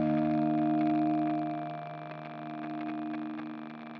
Rauschen bei Gitarrenaufnahme
Hallo, ich hab das Problem das bei meiner Gitarrenaufnahme ein rauschen ist und weiß leider nicht woran es liegt. Ich benutze Cubas Elements 10 und meine Gitarre (Fender Telecaster) ist mit dem UR12 über Klinke verbunden. In Cubase selber benutze ich STL AmpHub als virtuellen AMP.
Ich hoffe man hört das Störgeräusch raus.